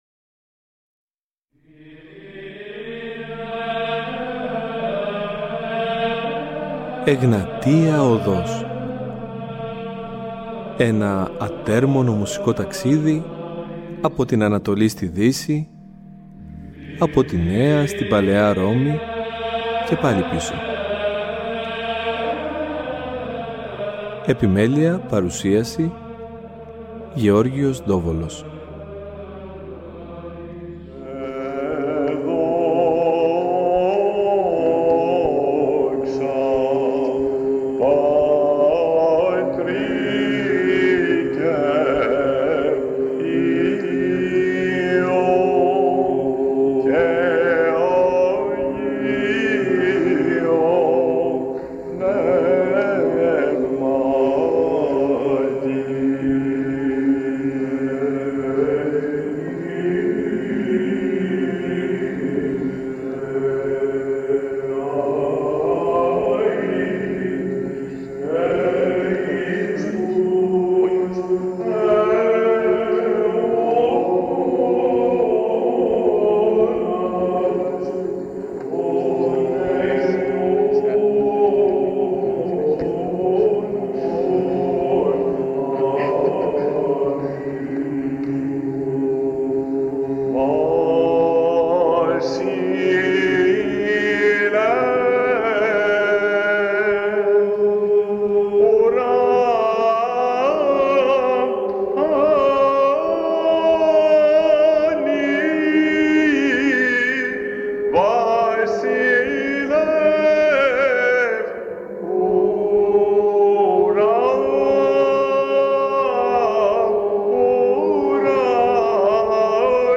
Στην θεματική αυτή εκπομπή θα ακουστούν σπάνιες ηχογραφήσεις από την εορτή της Πεντηκοστής αλλά και του Αγίου Πνεύματος από τις φωνές των Κωνσταντινουπολιτών ψαλτών
ΥΜΝΟΙ ΤΗΣ ΠΕΝΤΗΚΟΣΤΗΣ